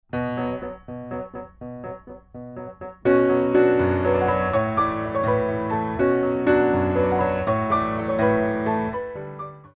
Warm-up Jump
6/8 - 32 with repeat